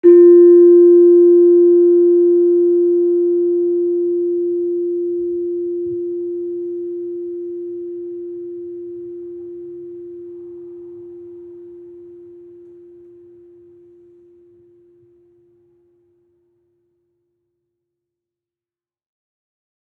Gamelan Sound Bank
Gender-2-F3-f.wav